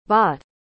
¿Cómo se pronuncia bought correctamente?
Se pronuncia /bɔːt/, algo parecido a «bot» en español, pero con una «o» bien abierta. La gh es muda, ni te preocupes por ella.